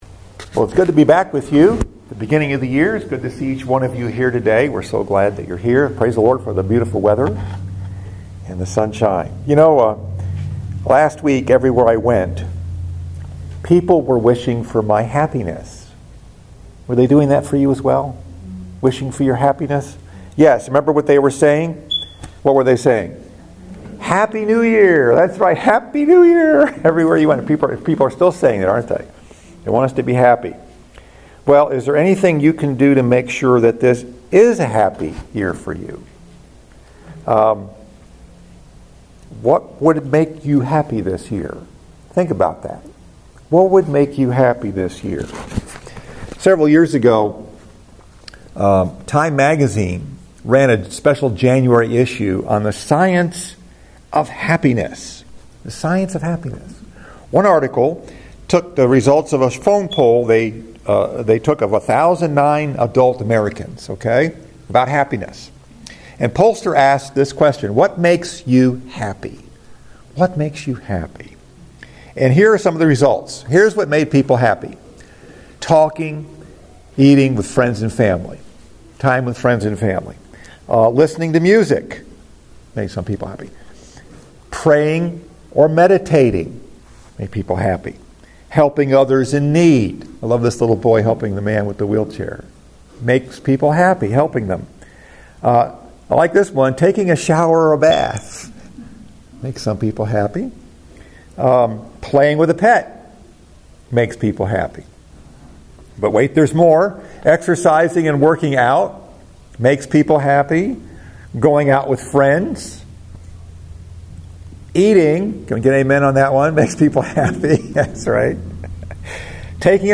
Message: “How To Be Happy” Scripture: Matthew 5:1-3